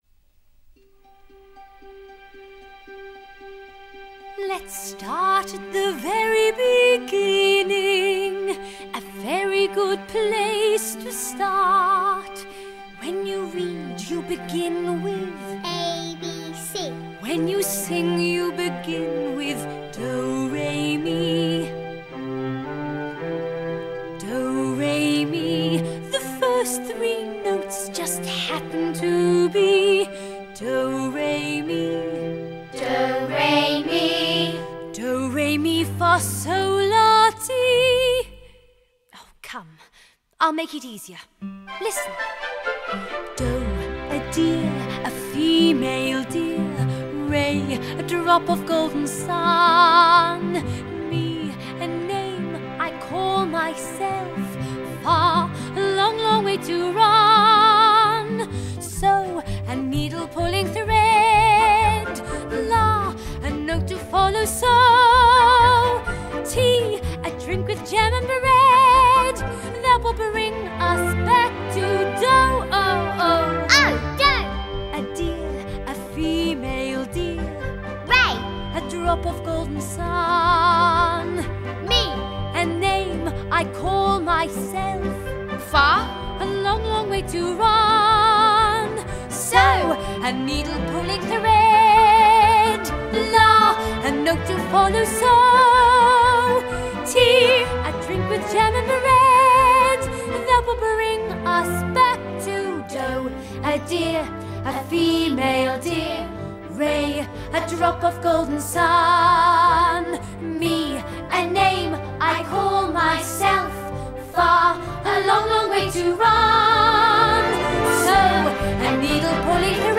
Lagu Kanak-kanak
Children's Song